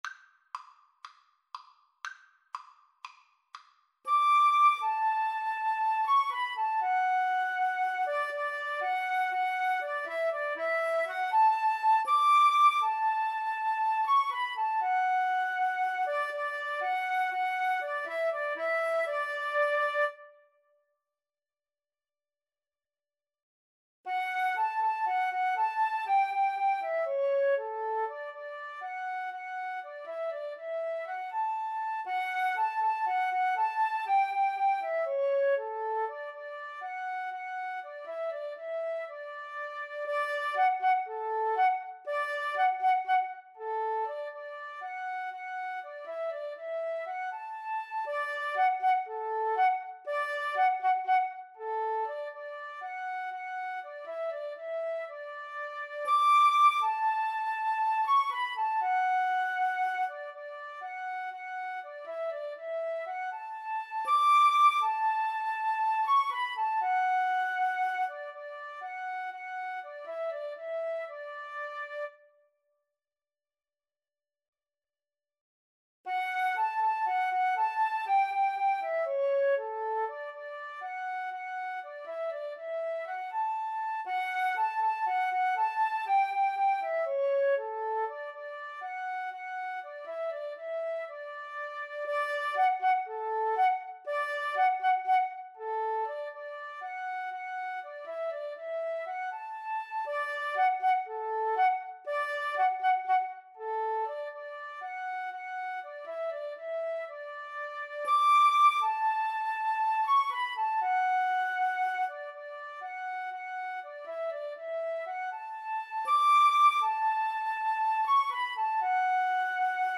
Fast =c.120